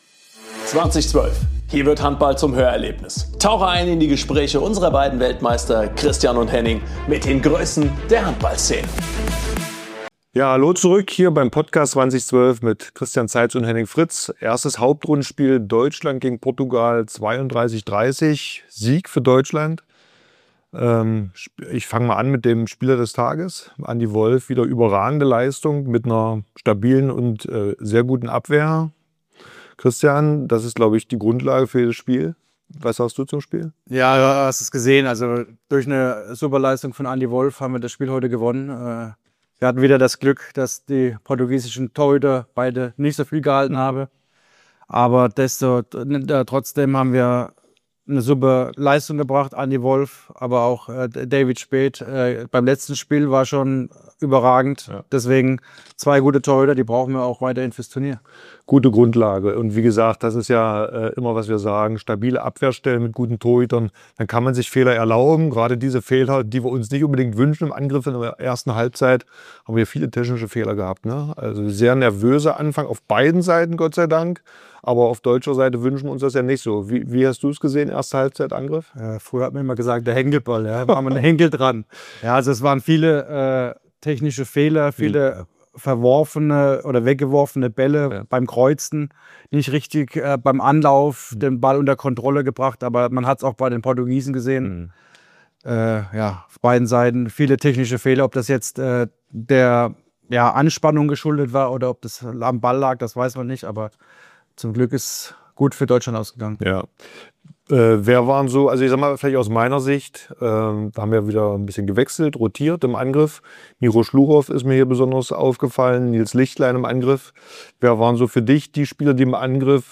Beschreibung vor 1 Monat Am Mikrofon: Christian Zeitz & Henning Fritz Zwei Weltmeister.